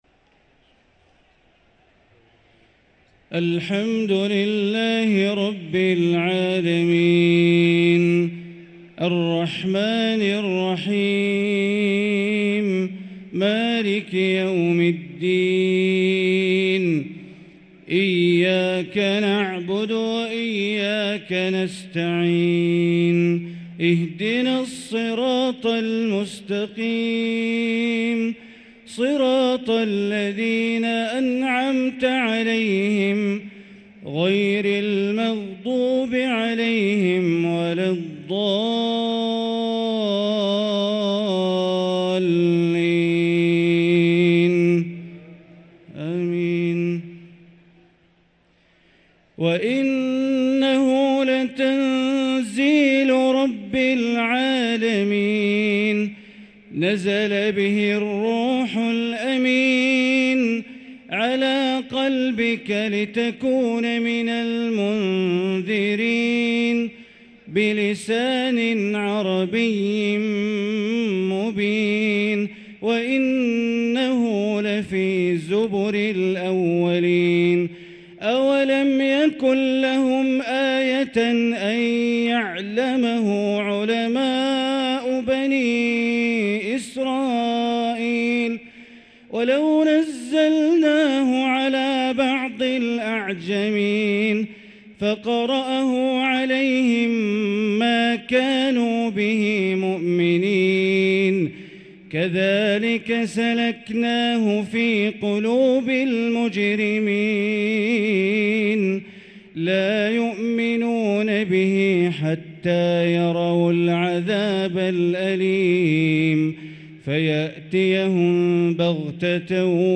صلاة العشاء للقارئ بندر بليلة 26 رمضان 1444 هـ
تِلَاوَات الْحَرَمَيْن .